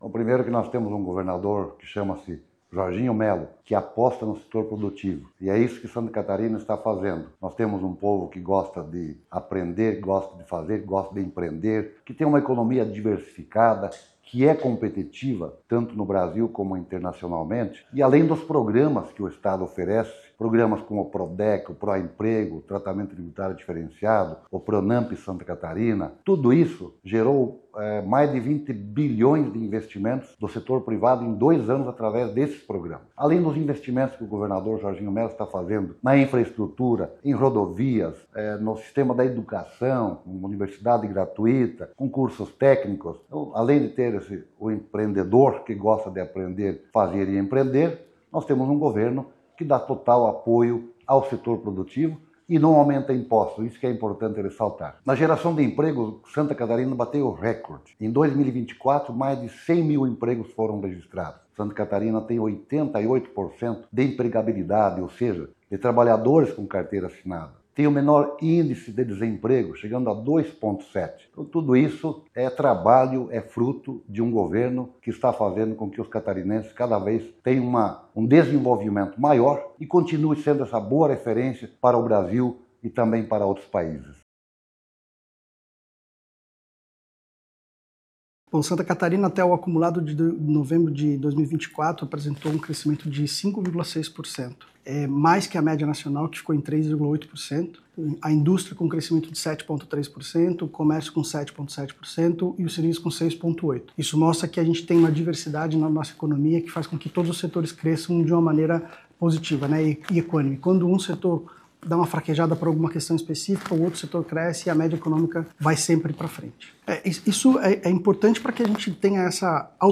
O secretário de Estado de Indústria, Comércio e Serviço, Silvio Dreveck, atribui o resultado positivo ao bom ambiente de negócios em Santa Catarina:
Para o secretário do Planejamento, Edgard Usuy, os números mostram a diversidade da economia catarinense, onde todos os setores contribuem positivamente para o resultado: